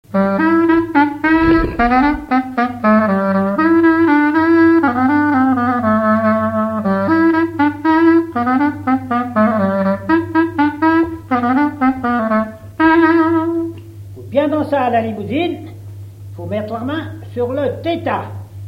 danse : ronde
Pièce musicale inédite